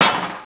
SwrdMtl3.WAV